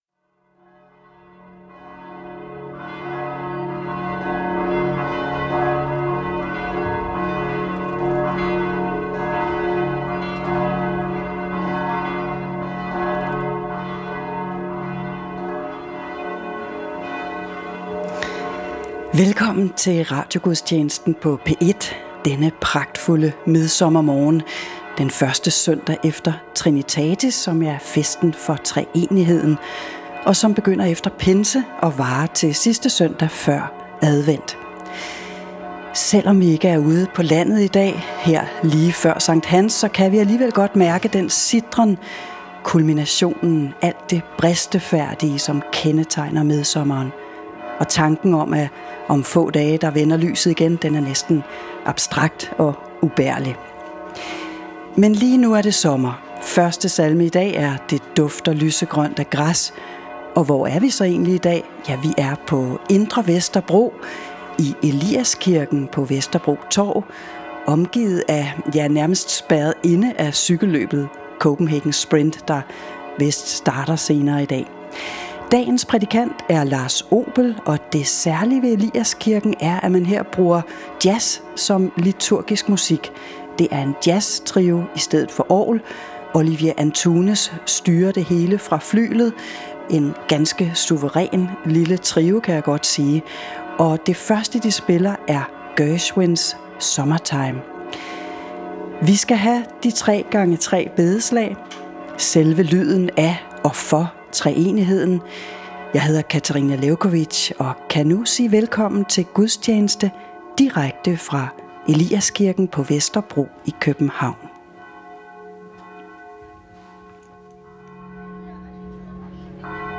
Direkte transmission af dagens højmesse fra en af landets kirker.